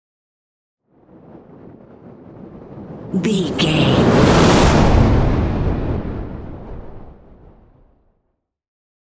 Whoosh fire large
Sound Effects
dark
intense
whoosh